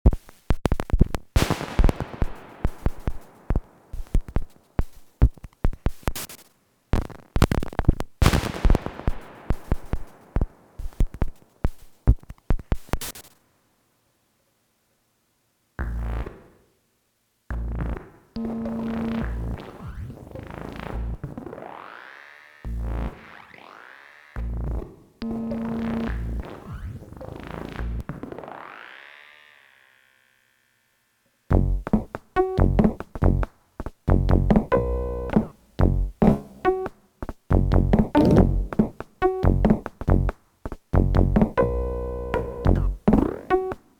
Here’s a few proof of concept ideas using the FX block as the only sound source. This isn’t a song, it’s just three different patterns played with a blank pattern separating them.
The main usability issue with this is that the sounds are all either extremely quiet or extremely loud if the delay feedback gets out of control.